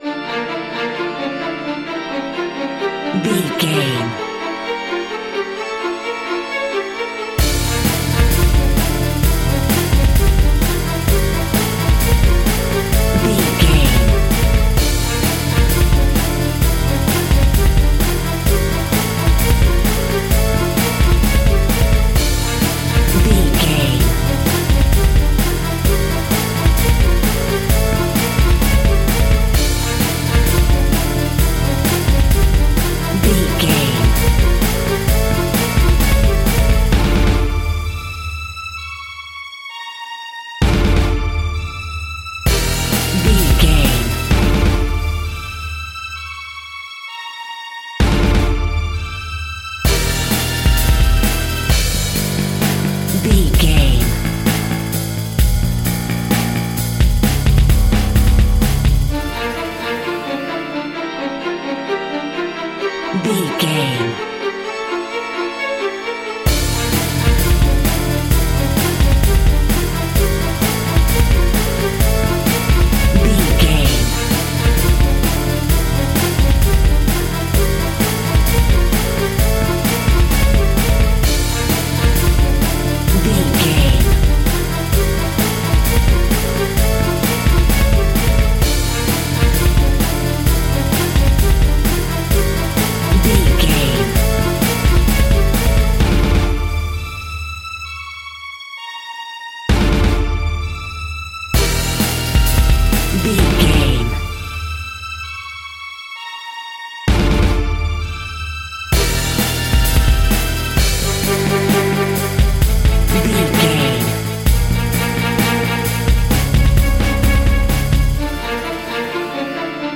In-crescendo
Aeolian/Minor
scary
ominous
dark
haunting
eerie
strings
drums
bass guitar
piano
organ
synth
pads